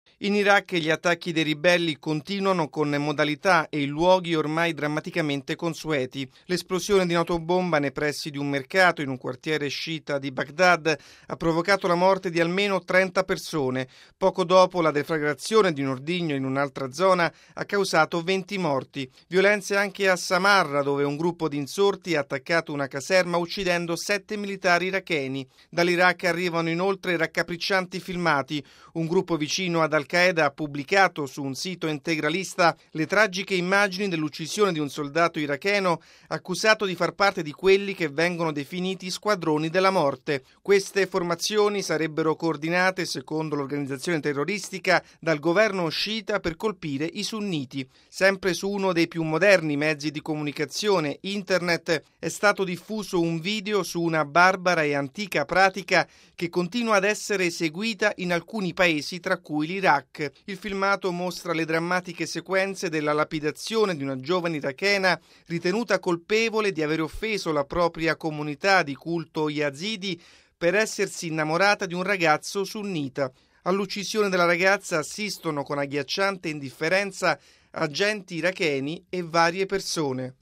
La violenza continua a devastare l’Iraq: alla notizia di nuovi attentati a Baghdad, costati la vita ad oltre 50 persone, si aggiungono le agghiaccianti immagini di filmati con le tragiche sequenze dell’uccisione di un soldato iracheno e della lapidazione di una giovane donna. Il nostro servizio: RealAudio